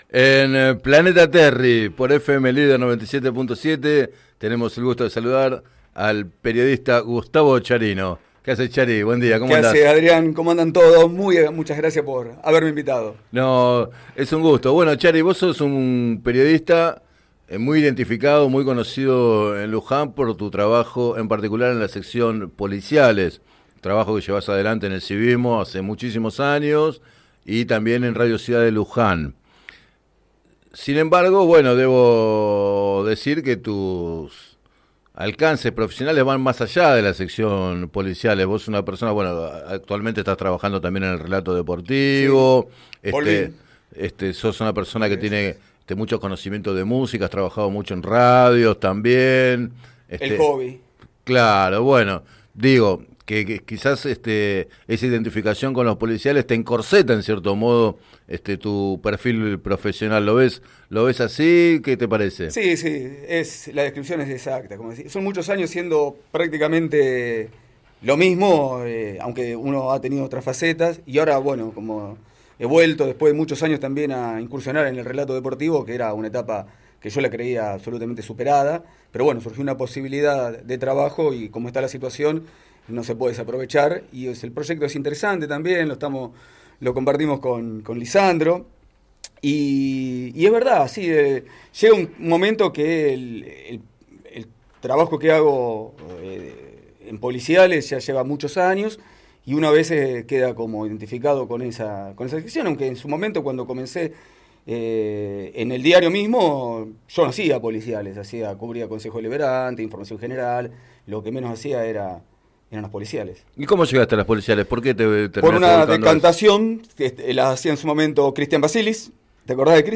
Entrevistado en el programa Planeta Terri